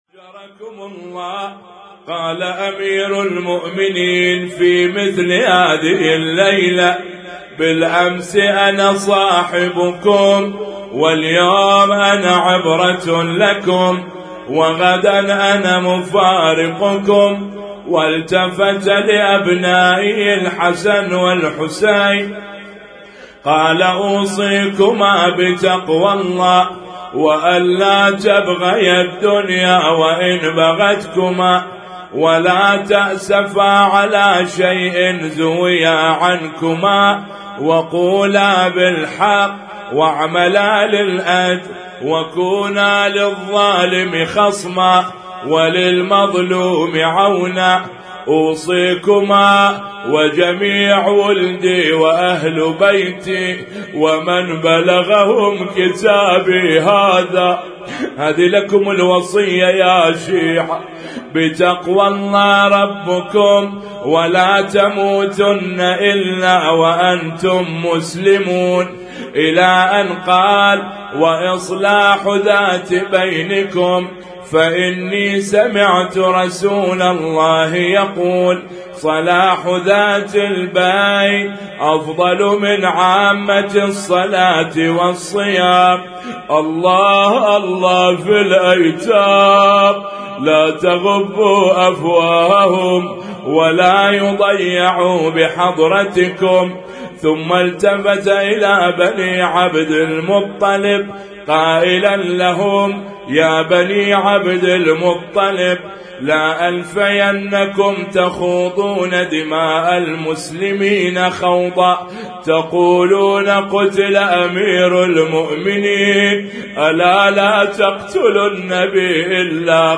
Husainyt Alnoor Rumaithiya Kuwait
اسم التصنيف: المـكتبة الصــوتيه >> الصوتيات المتنوعة >> النواعي